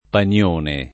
[ pan L1 ne ]